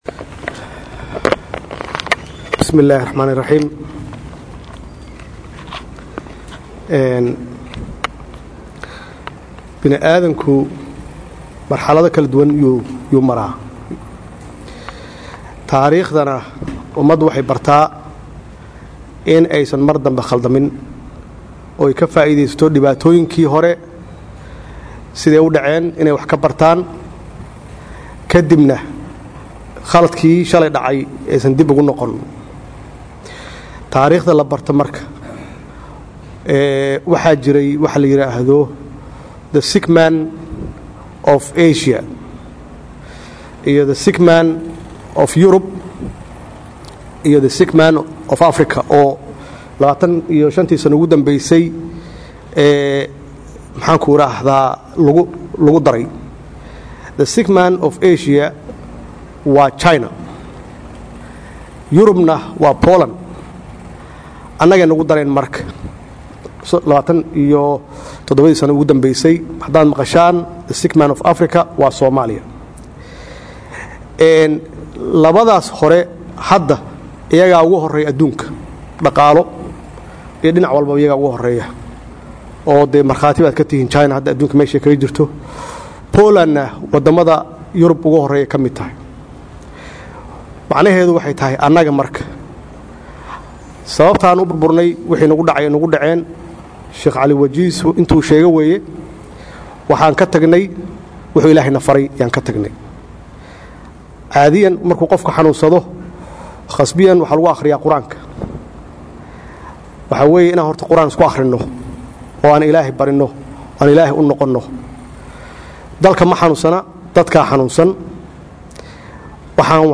Dhageyso Khudbadda Madaxweyne Farmaajo Maanta Ka Jeediyey Masjidka Madaxtooyada
Akhristayaasheena sharafta leh waxaan halkaan idiinkugu soo gubdinaynaa khudbadd uu maanta salaadda jimcaha kadib ka jeediyey Madaxweynaha Jamhuuriyadda Federaalka Soomaaliya